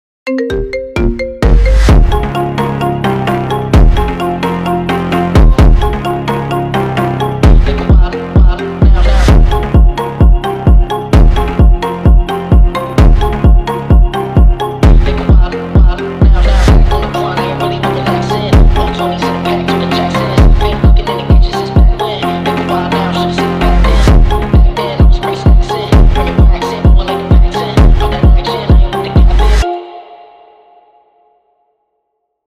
Categoría Marimba Remix